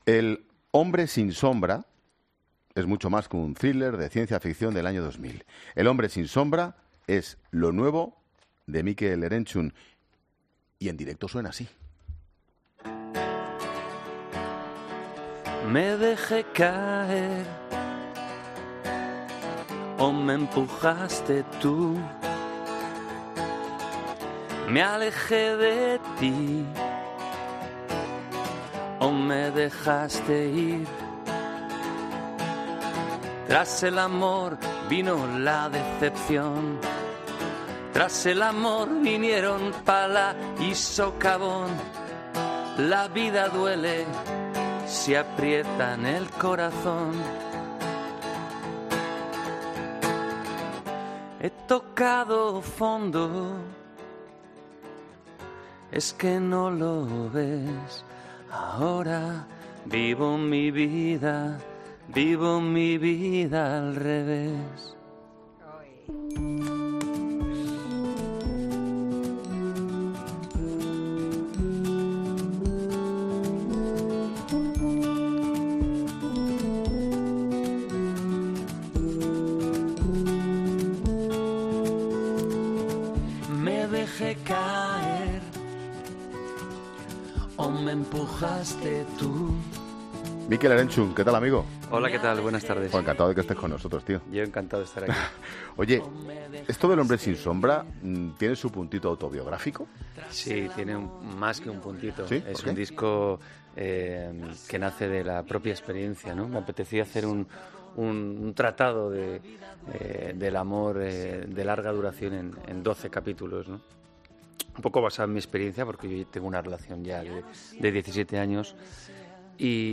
El cantante Mikel Erentxun ha visitado 'La Tarde' de Ángel Expósito y ha presentado su nuevo disco, 'El hombre sin sombra'.Tras más de 20 años en...